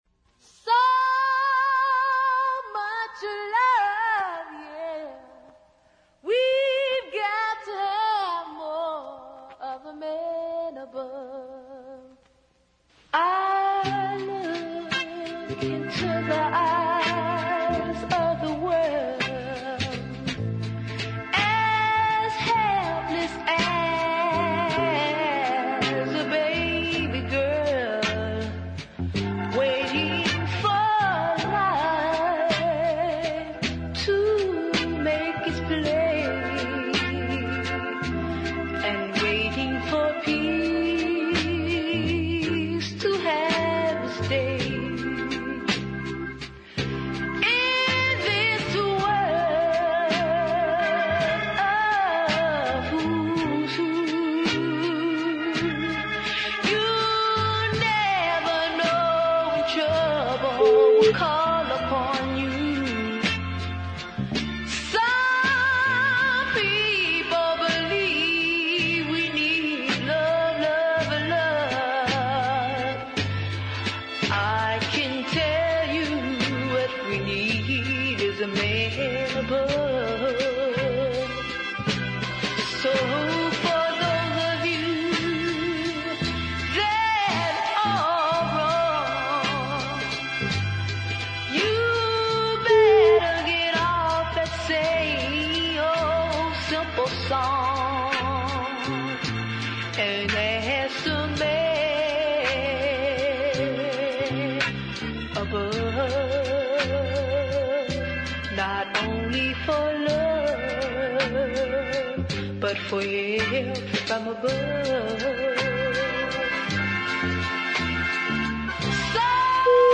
high gospel
a tuneful ballad